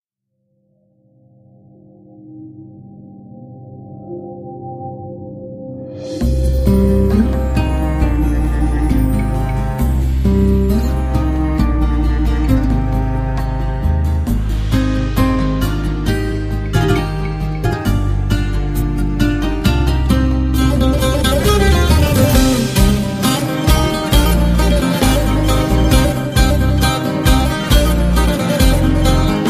Ζεϊμπέκικο του 2007.